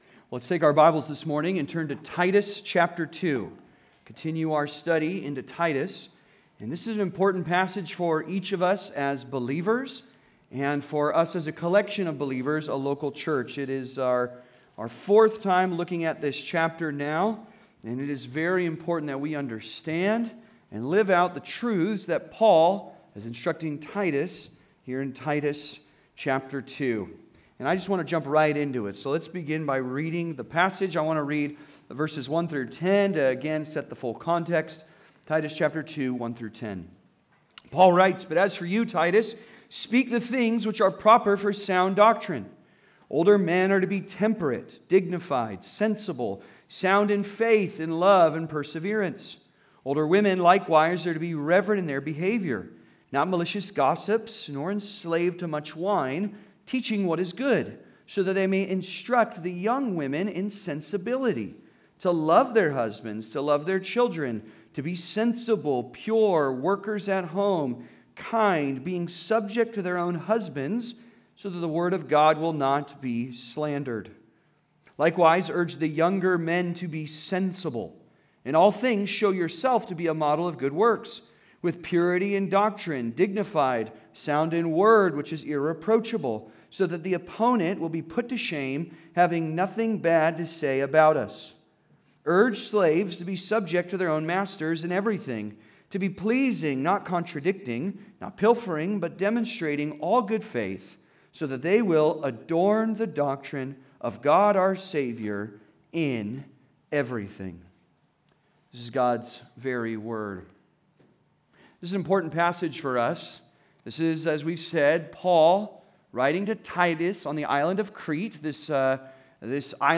Living our Doctrine Part 2 (Sermon) - Compass Bible Church Long Beach